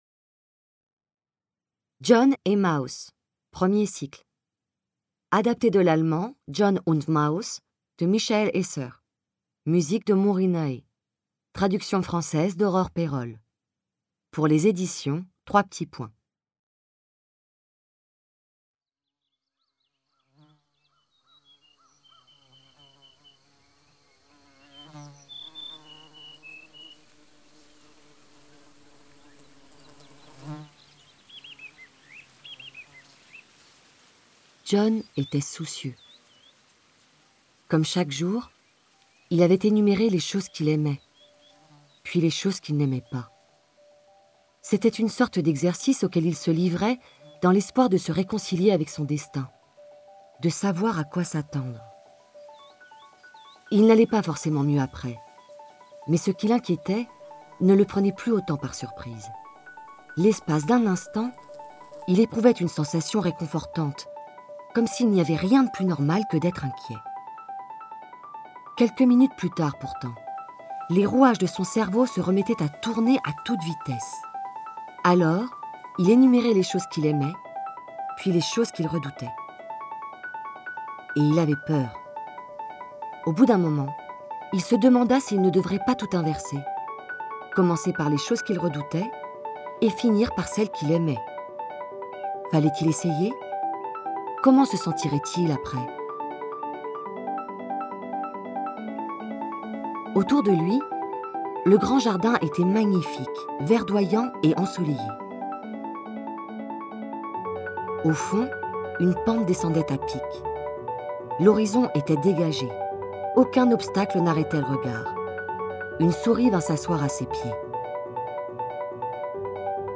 La mise en scène est une performance dramaturgique et musicale. Le « dialogue » de John (dont les pensées et répliques sont livrées par le narrateur) et Maus, résonne ainsi dans celui du texte et de la mise en son(s) : les mots sont soulignés, rythmés, soutenus par le paysage sonore électro.